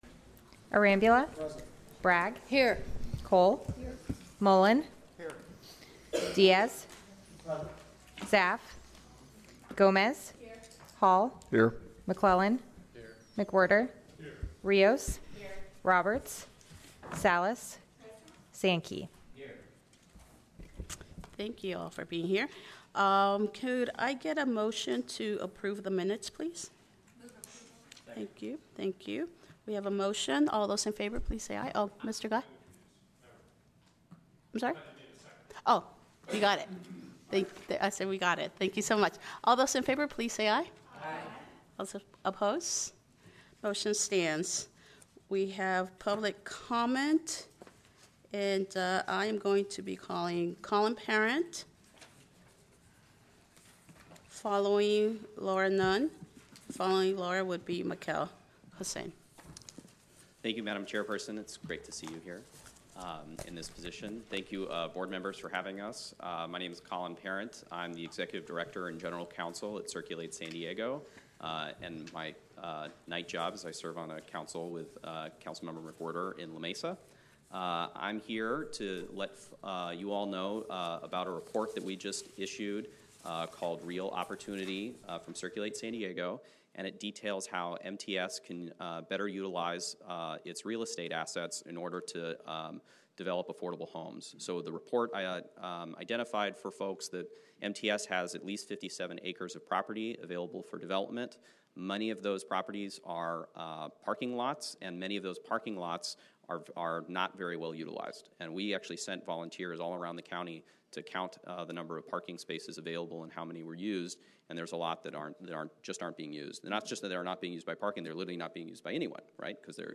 Meeting Type Board Meeting